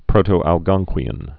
(prōtō-ăl-gŏngkwē-ən, -kē-ən)